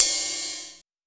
VEC3 Ride
VEC3 Cymbals Ride 15.wav